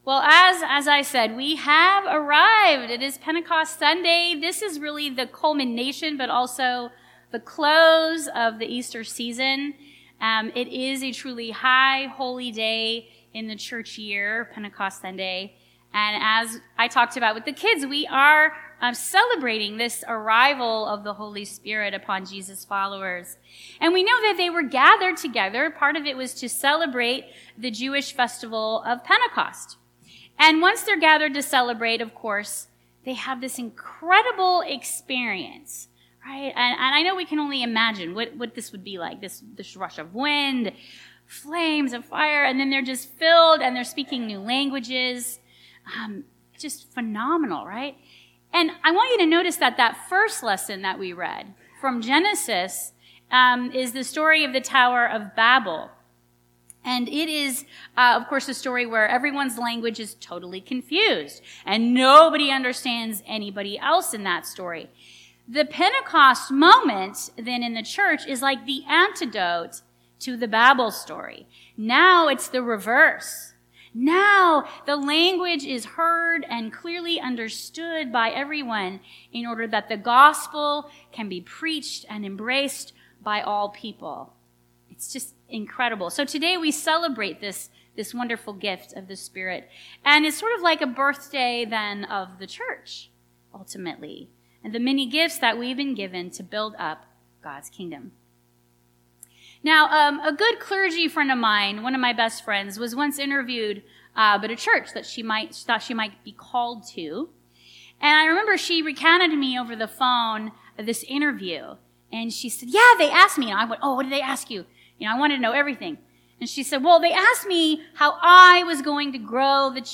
Listen to our recorded sermons in high-quality .mp3 format.